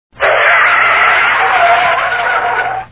car_skid.wav